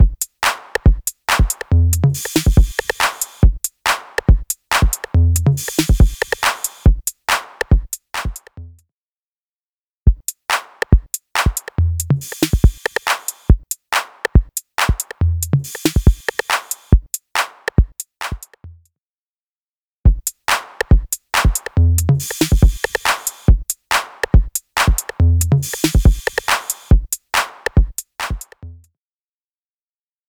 Invigorate | 808 Drums | Preset: Drum Machine Grit
Invigorate-808-Drums-Drum-Machine-Grit-CB.mp3